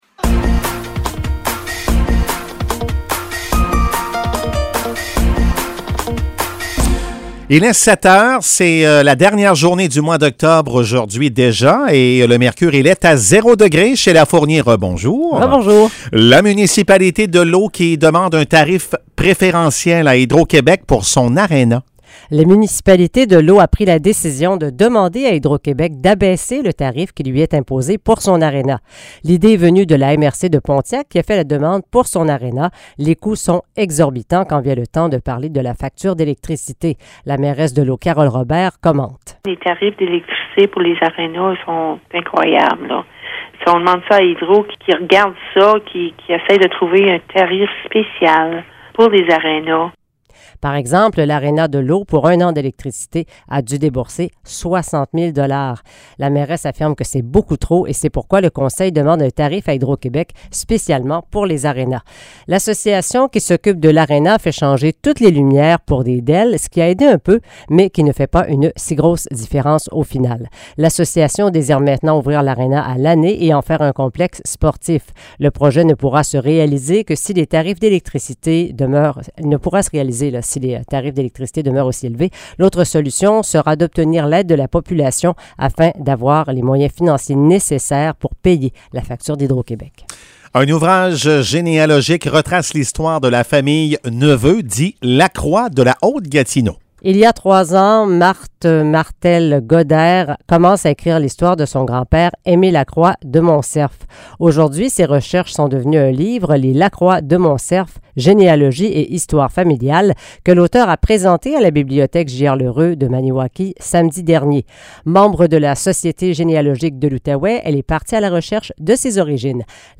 Nouvelles locales - 31 octobre 2022 - 7 h